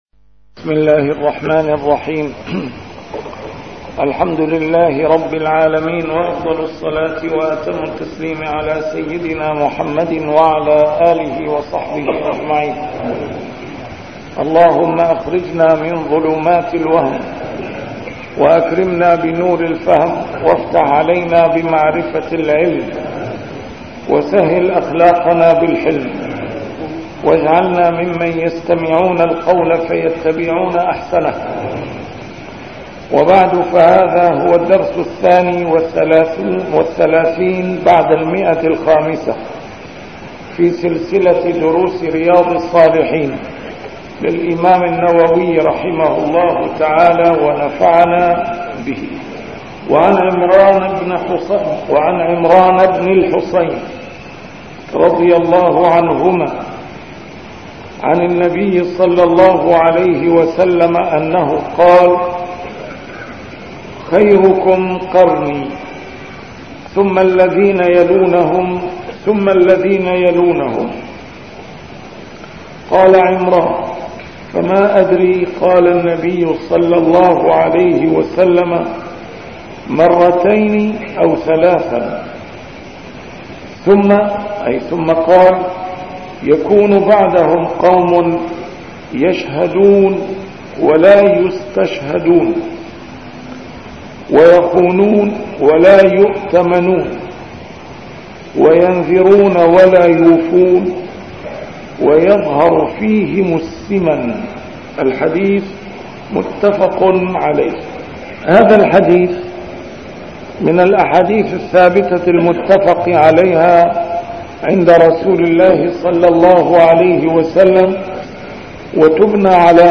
A MARTYR SCHOLAR: IMAM MUHAMMAD SAEED RAMADAN AL-BOUTI - الدروس العلمية - شرح كتاب رياض الصالحين - 532- شرح رياض الصالحين: فضل الجوع